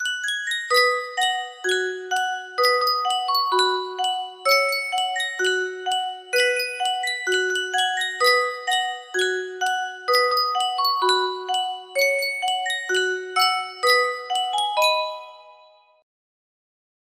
BPM 64